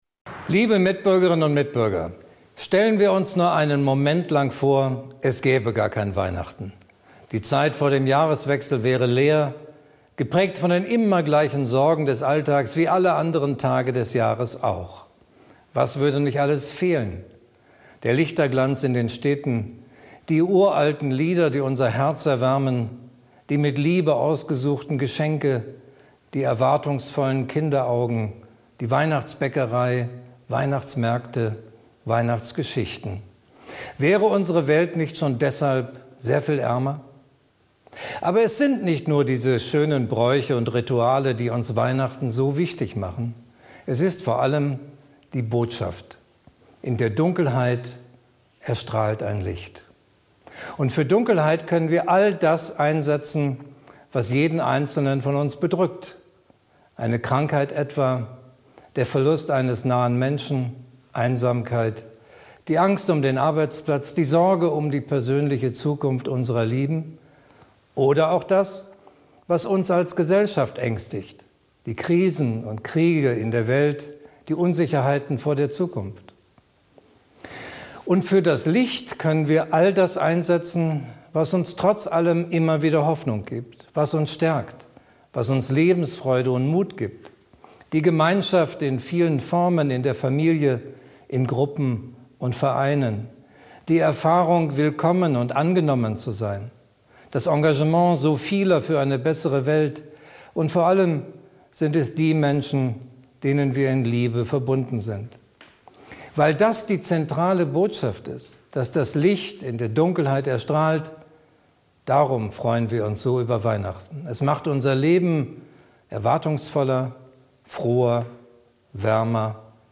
Weihnachtsansprache des Bundespräsidenten Frank-Walter Steinmeier 25.12.2025